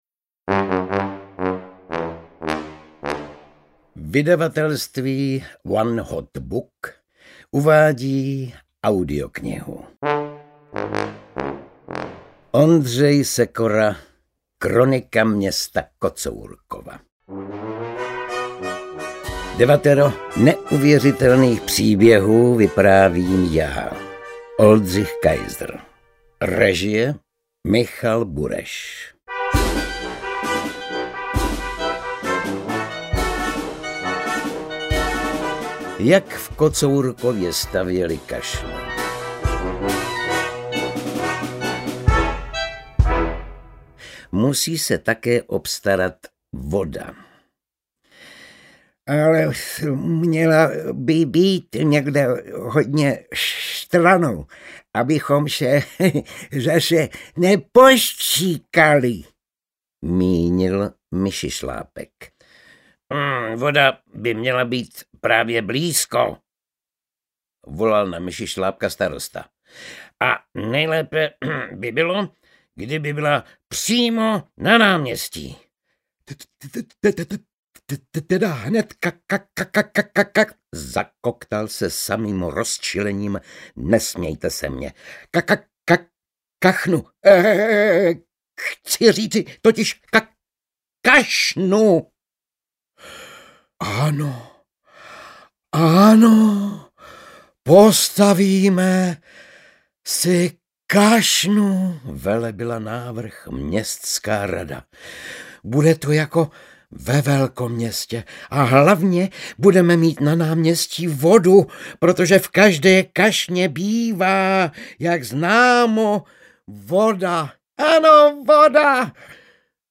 Kronika města Kocourkova audiokniha
Ukázka z knihy
• InterpretOldřich Kaiser